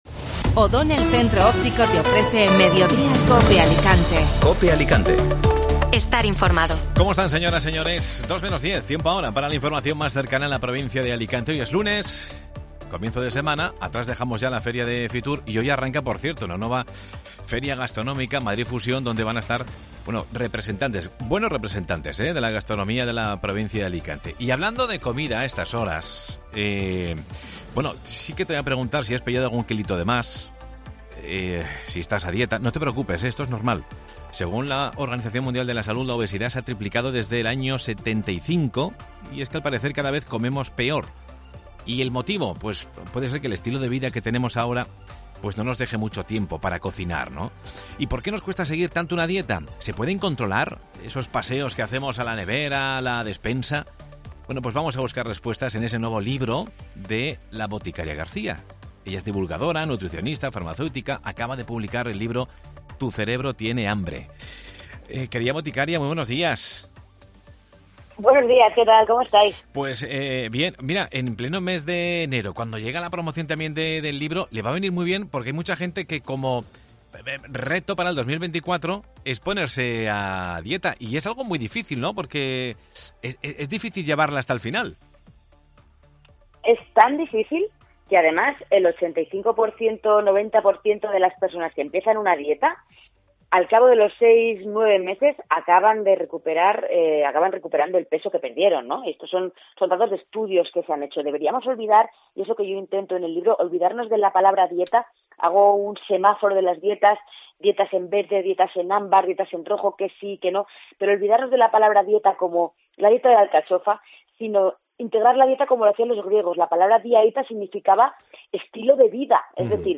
Boticaria García presenta "Tu cerebro tiene hambre" el próximo miércoles en Alicante. Escucha la entrevista en Mediodía COPE Alicante